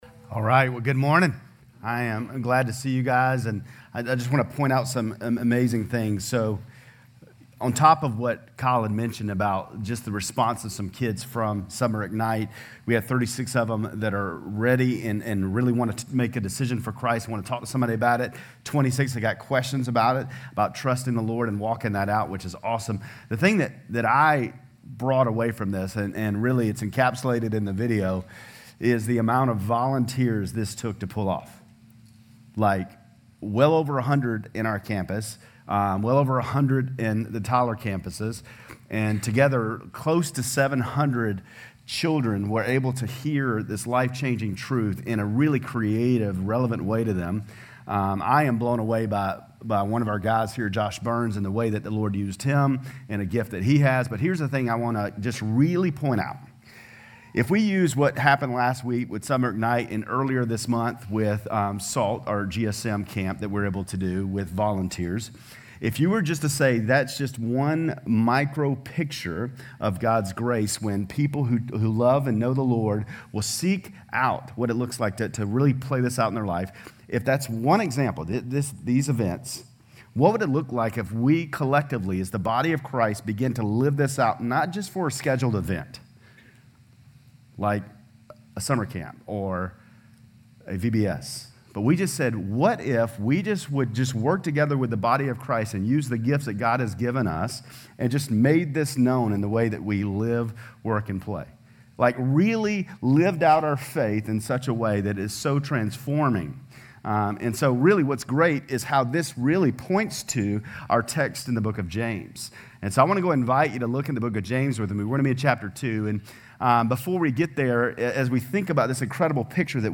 GCC-LD-July-2-Sermon.mp3